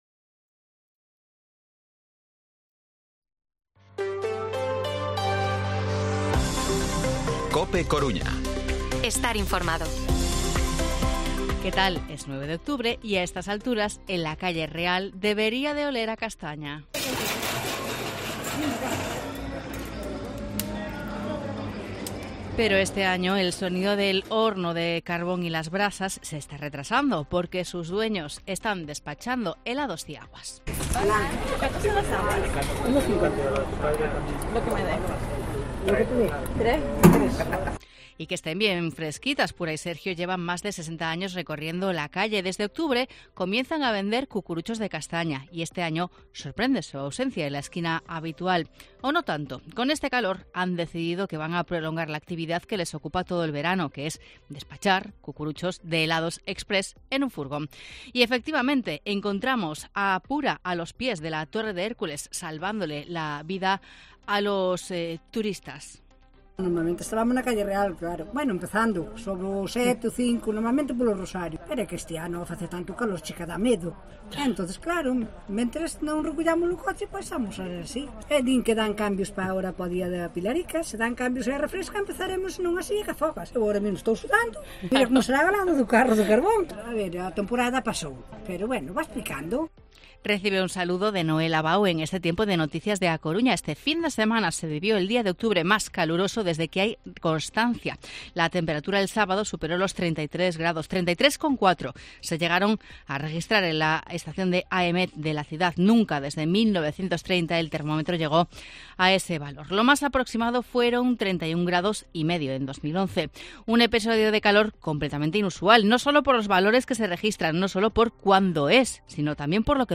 Informativo Mediodía COPE Coruña lunes, 9 de octubre de 2023 14:20-14:30